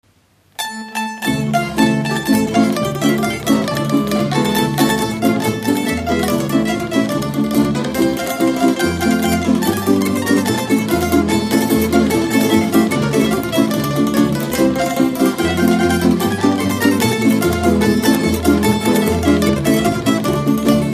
Dallampélda: Hangszeres felvétel
Alföld - Bács-Bodrog vm. - Bátmonostor
tambura (prím)
tambura (basszprím)
bőgő
kontra
harmonika
Műfaj: Oláhos
Stílus: 7. Régies kisambitusú dallamok
Kadencia: 1 (1) 5 1